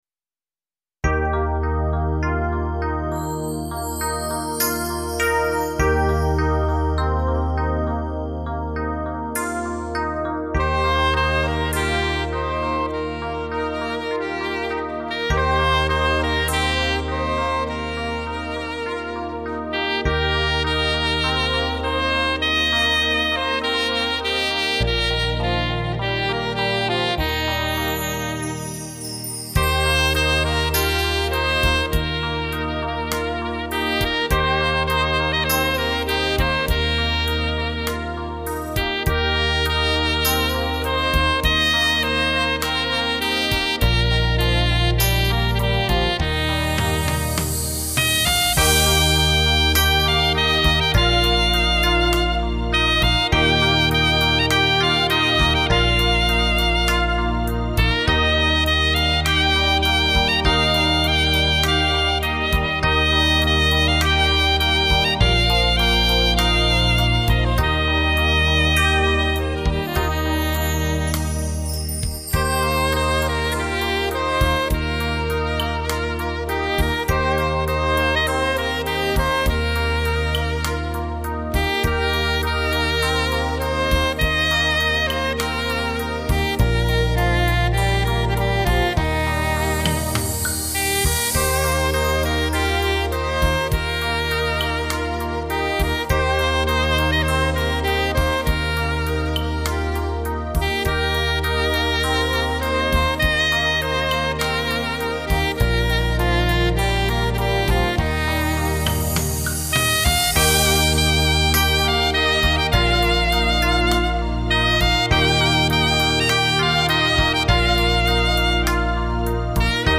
萨克斯